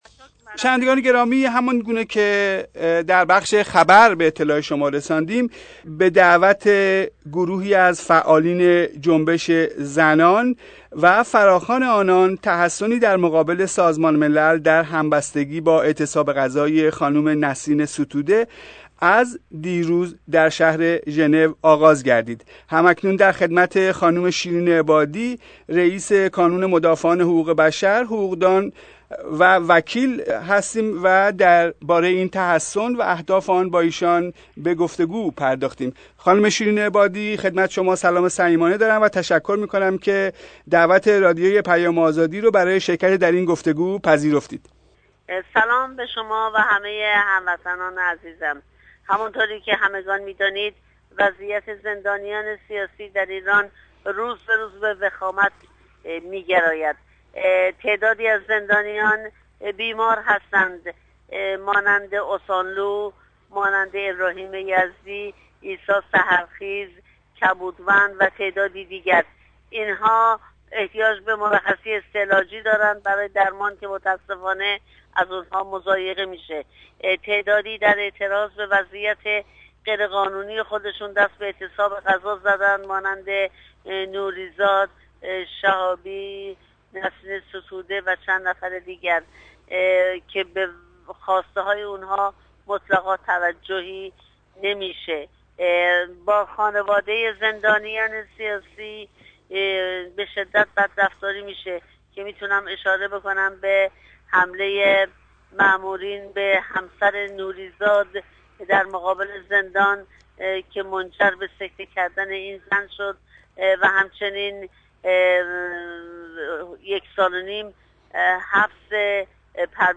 گفت و گو با خانم شیرین عبادی را بشنوید